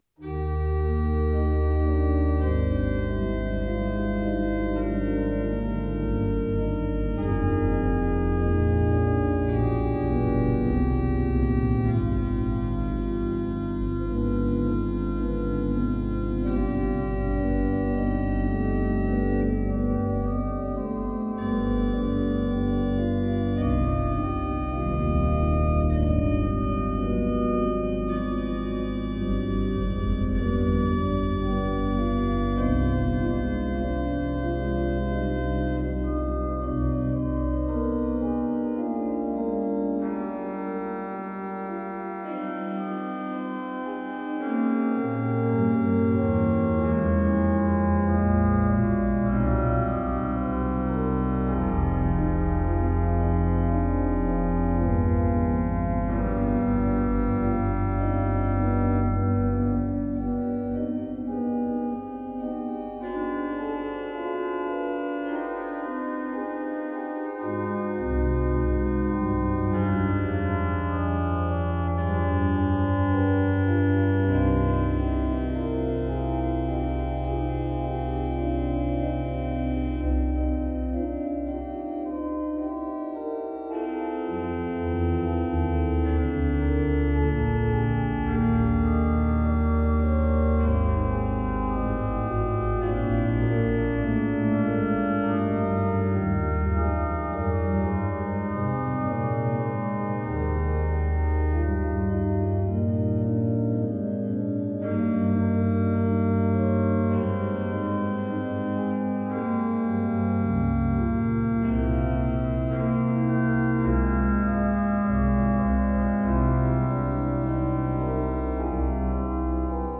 Die Komposition beginnt mit einer durchgehenden Achtelbewegung, die nicht unterbrochen wird. Auch in der Begleitung finden sich Fragmente der Melodie wieder.